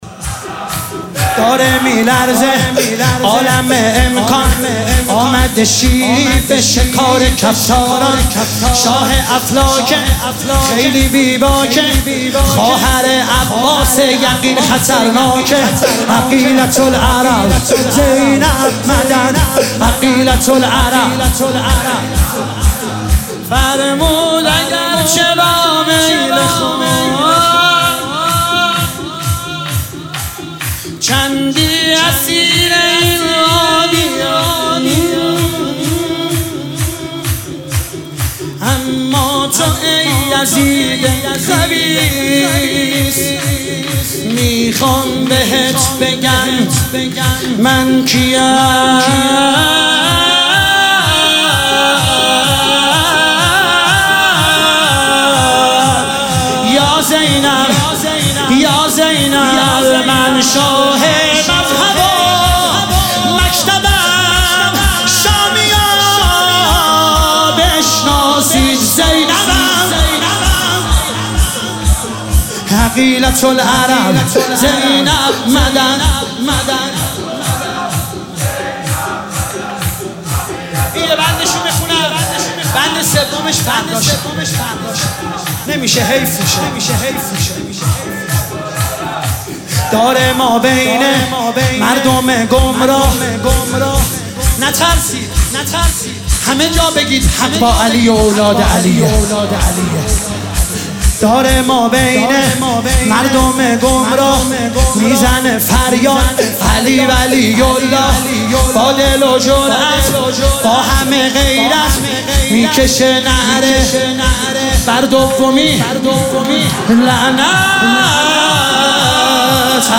دانلود فایل صوتی مداحی شور حضرت زینب